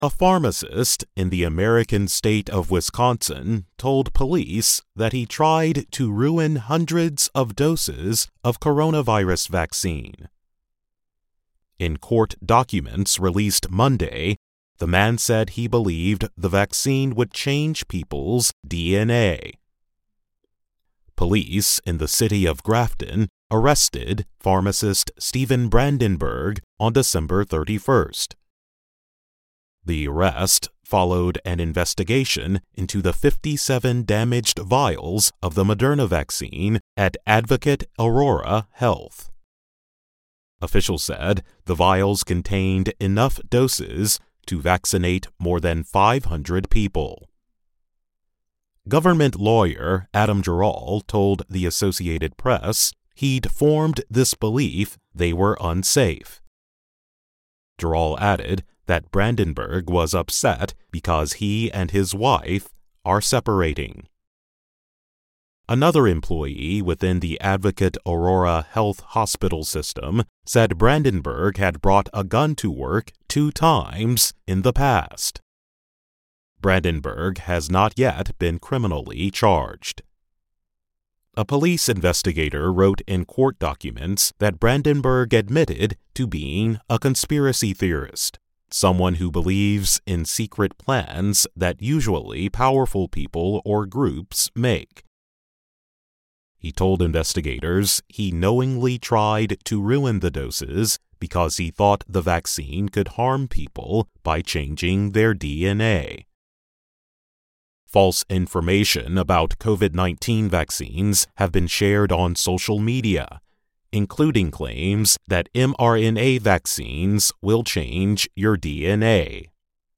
慢速英语:美国一药剂师破坏几百剂新冠疫苗被捕